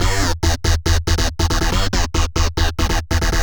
FR_Synco_140-E.wav